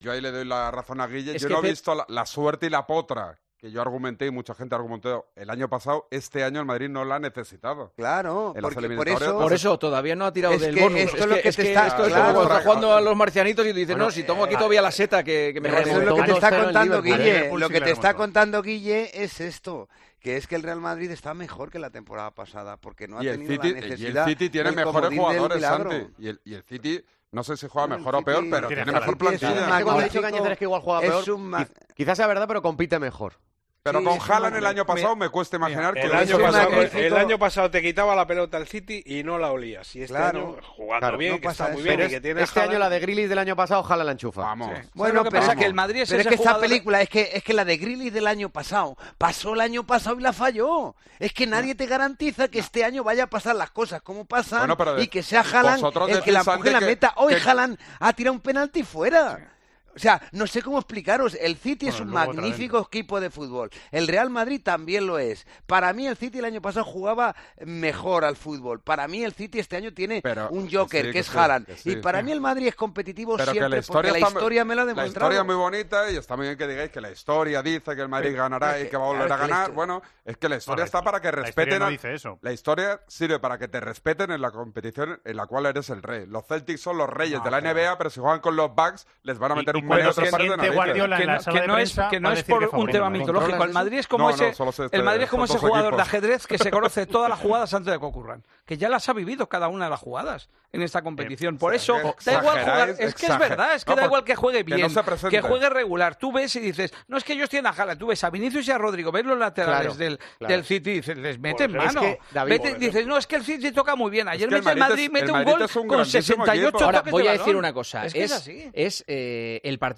Los tertulianos del 'Tiempo de Opinión' hacen su particular porra dando el porcentaje de opciones que tiene cada uno de los equipos en las...